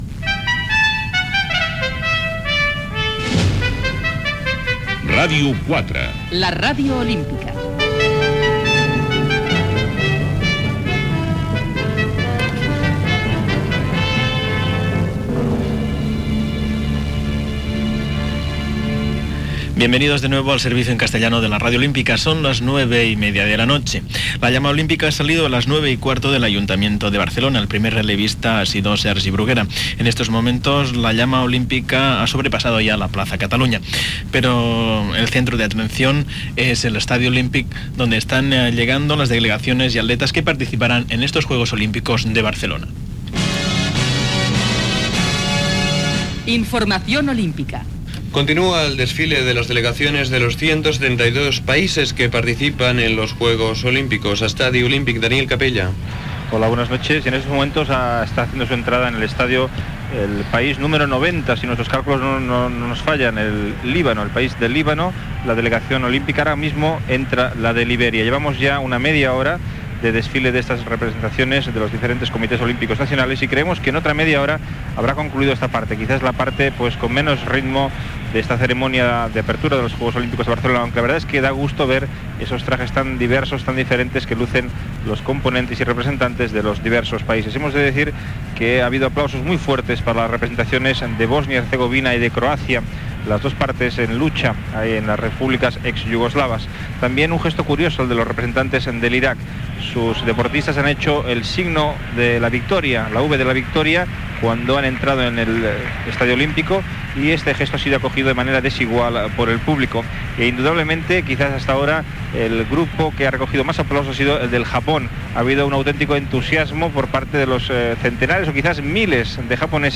98486e56e116dd866f334faf3d92cb12c37779c3.mp3 Títol Ràdio 4 la Ràdio Olímpica Emissora Ràdio 4 la Ràdio Olímpica Cadena RNE Titularitat Pública estatal Descripció Indicatiu de la ràdio, "Informació olímpica": connexió amb l'Estadi Lluís Companys on s'està fent la cerimònia d'obertura dels Jocs Olímpics de Barcelona 1992, recorregut de la torxa Olímpica per Barcelona, ambient a fora de l'estadi.
Transmissió de la cerimònia inaugural.